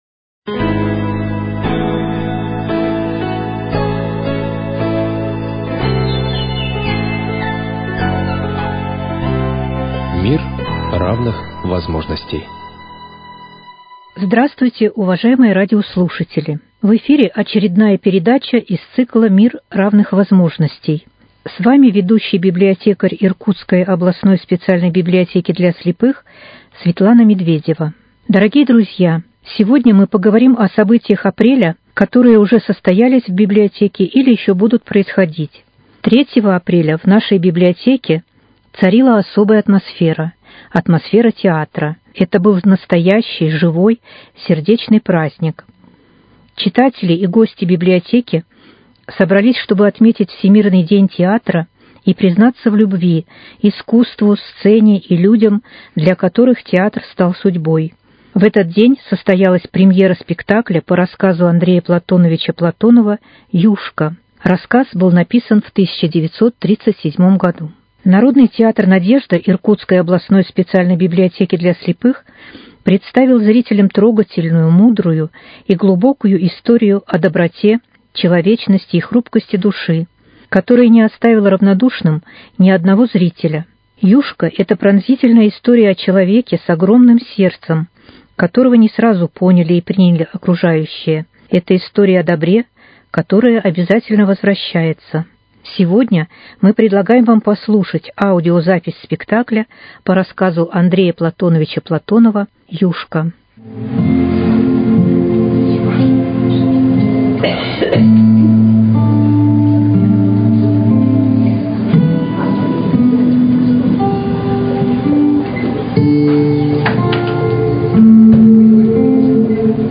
Народный театр "Надежда" Иркутской областной специальной библиотеки для слепых представляет премьеру спектакля по рассказу Андрея Платонова "Юшка".